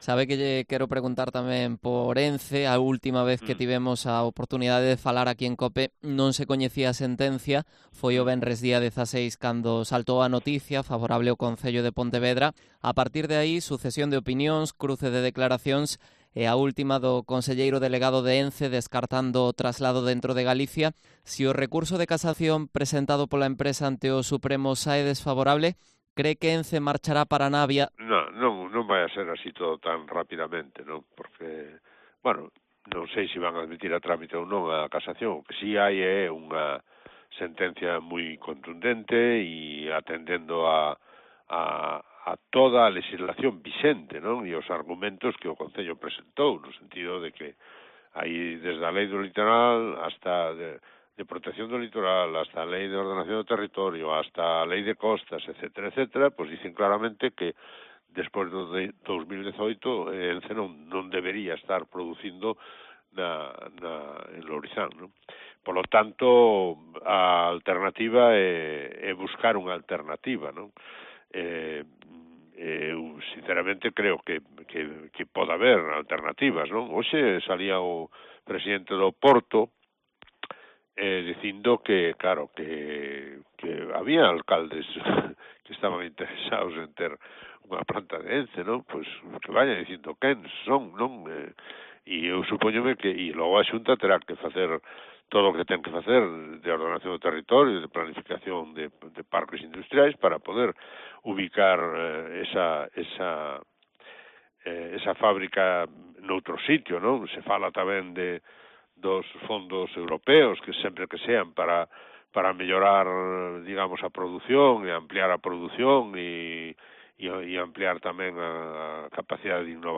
Entrevista a Miguel Anxo Fernández Lores, alcalde de Pontevedra